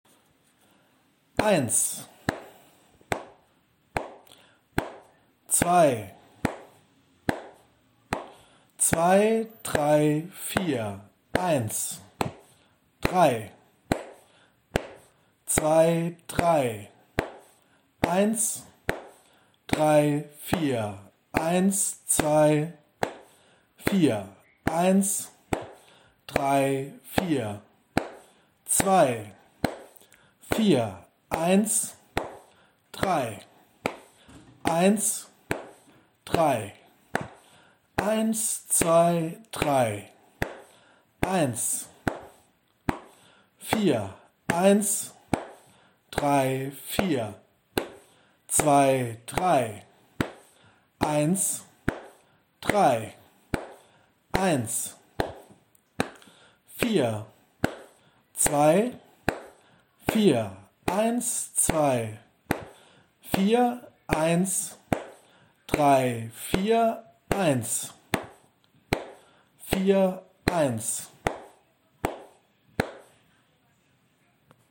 Spreche den Rhythmus auf folgende Art: "+ wird zu und " 1+2+3_4+ | 1+2___4 | 1+2+3_4+ | 1_______ | Hier von mir eingesprochen: